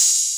Open Hats
Open-Hat - [Metro].wav